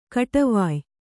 ♪ kaṭavāy